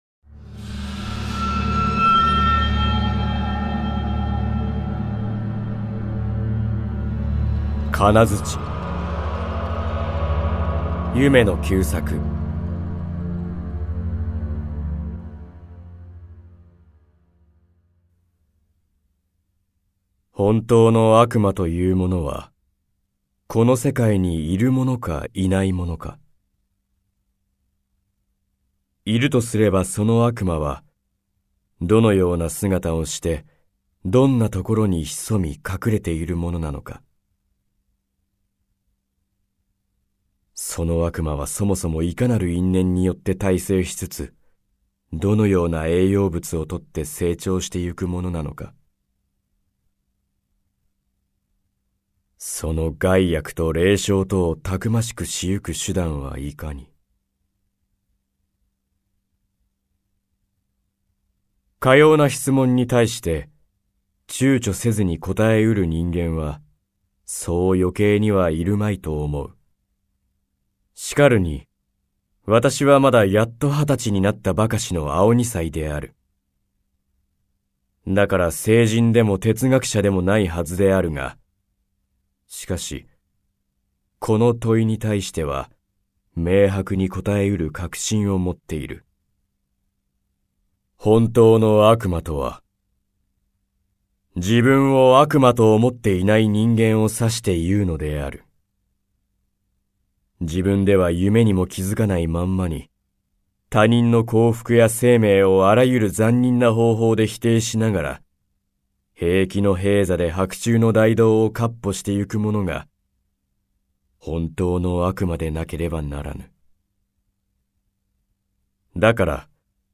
[オーディオブック] 夢野久作「鉄鎚」